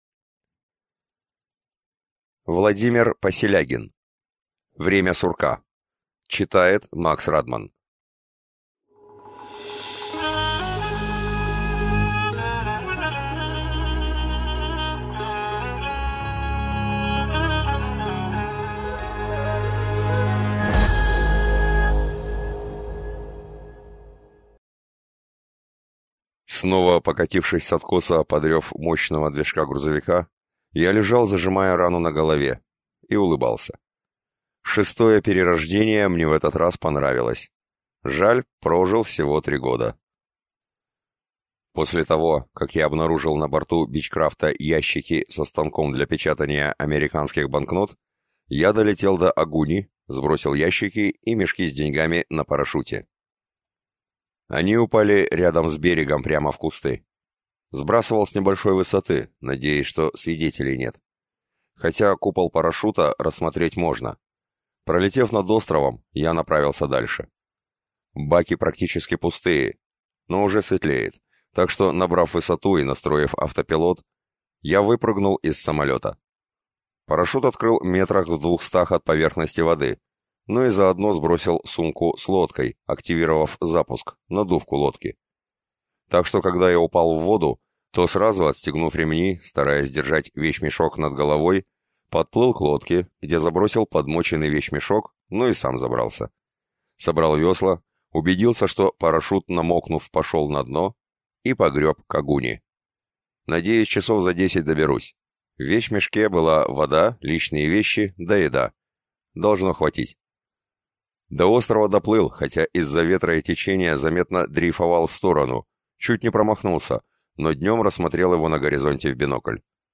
Аудиокнига Время сурка | Библиотека аудиокниг
Прослушать и бесплатно скачать фрагмент аудиокниги